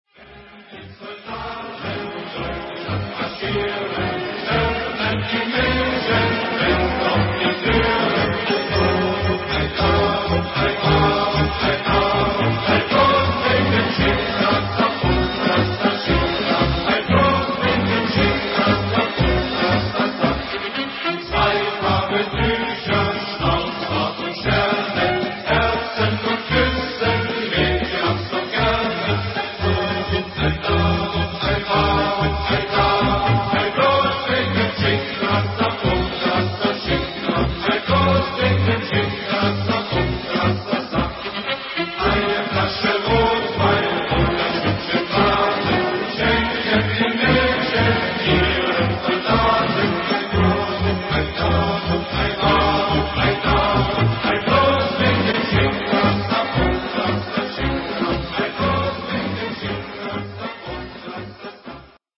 Когда в памяти возникает эта маршевая песня, то невольно представляешь себе беспощадное июльское солнце, пыльную степную дорогу, сожжённую траву, сожжённые дома, шагающих бодрых молодчиков с закатанными рукавами и со «шмайсерами» наперевес…